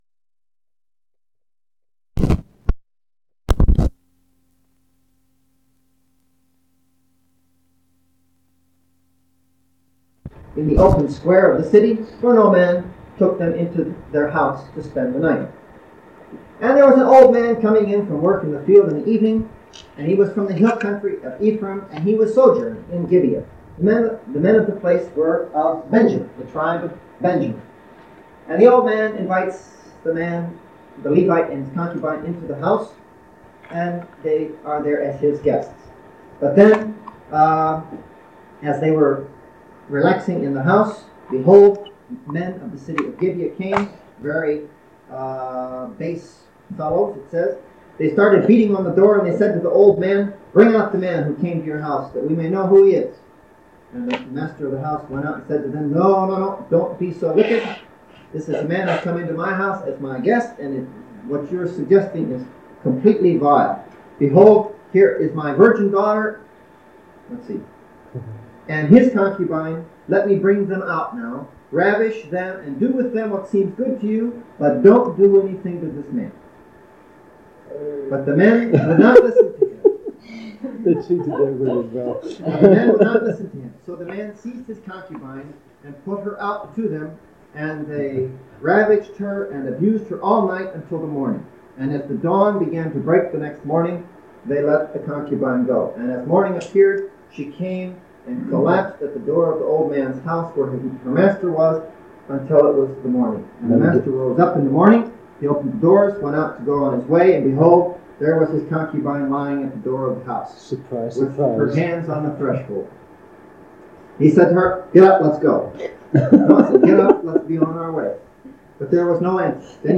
End of previous lecture, side 2 = Iron Age IIA (4)
Format en audiocassette ID from Starchive 417960 Tag en Iron Age Item sets ACOR Audio-visual Collection Media April_1_1979_end_access.mp3